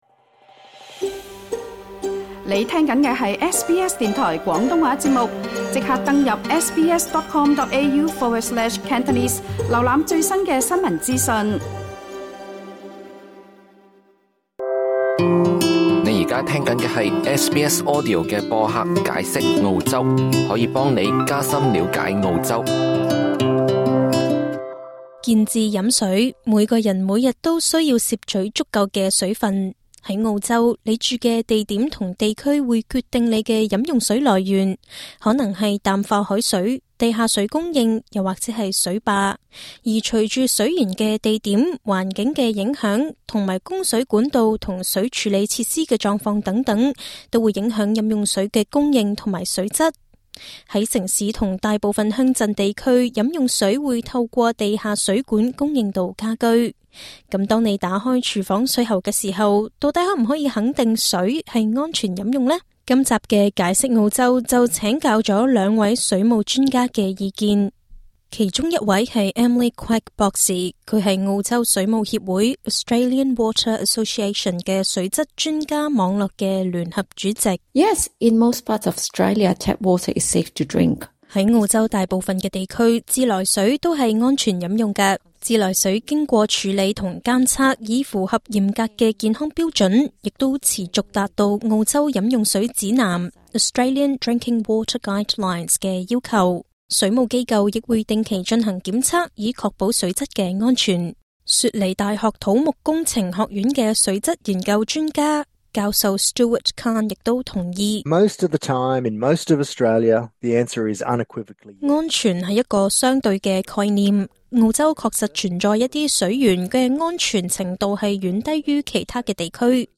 由於全國各地的飲用水供應情況和水質存在差異，我們應如何確定水是否安全飲用？我們請來水務專家為大家解答問題及其他相關疑問。